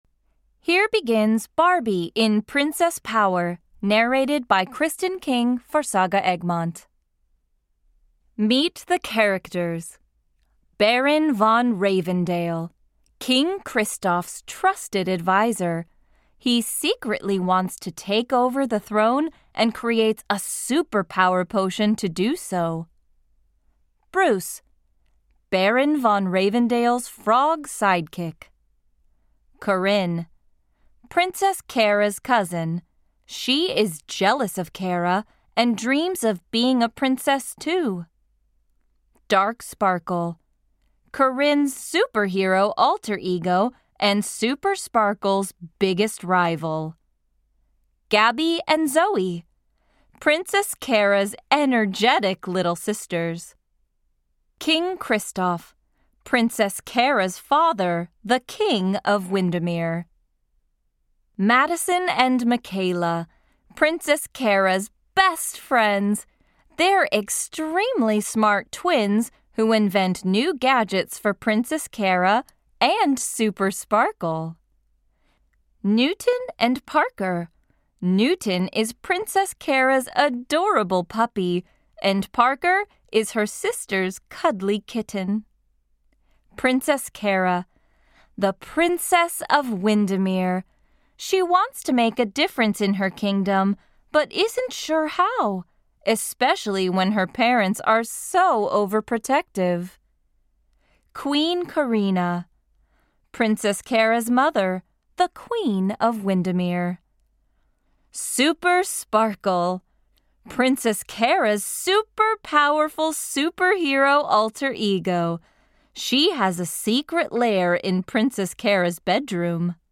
Barbie - Princess Power (EN) audiokniha
Ukázka z knihy